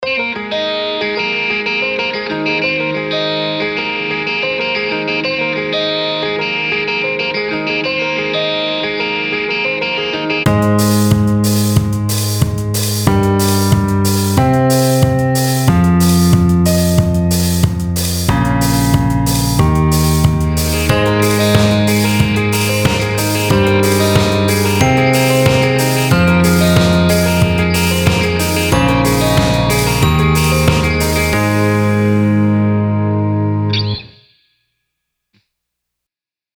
イントロのリフはA♭add9という和音を分解して弾いています。
イントロを例に4小節ずつ展開していきます
1.リフ
3.リフとコード